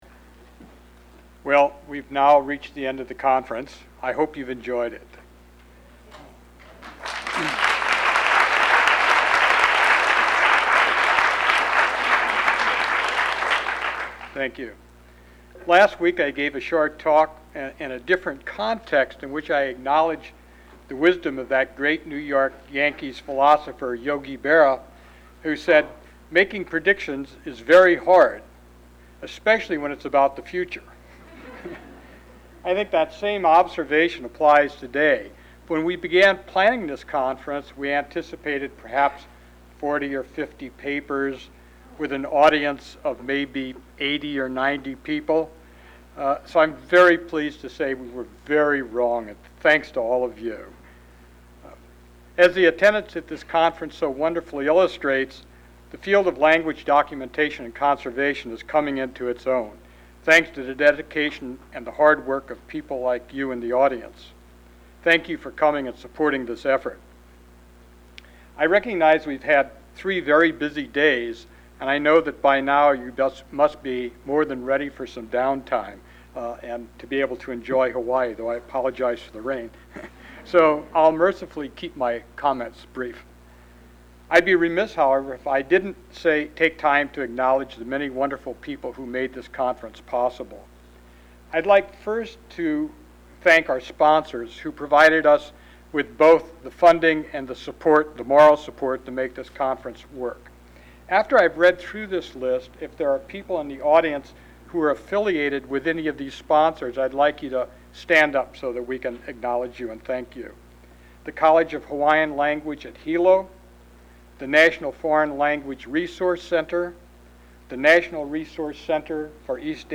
Closing speech
The closing speech made at the conference